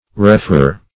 Referrer \Re*fer"rer\ (r?*f?r"r?r), n.